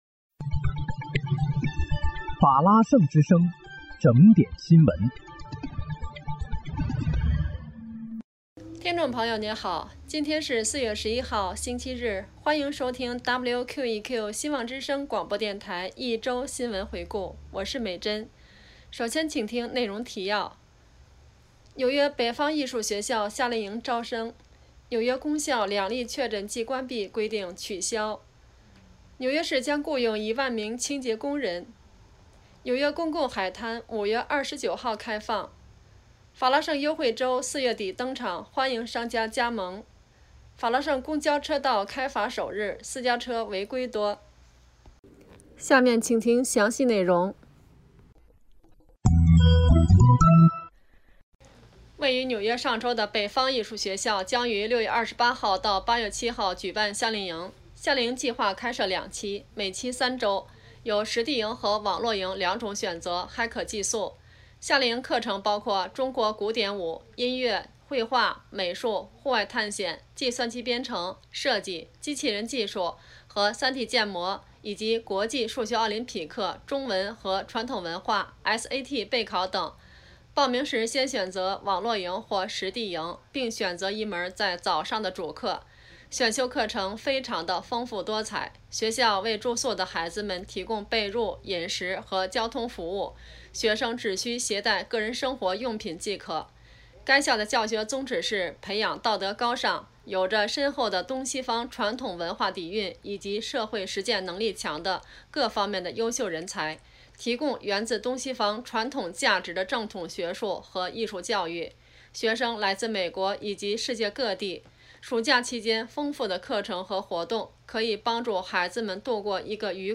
4月11日(星期日)一周新闻回顾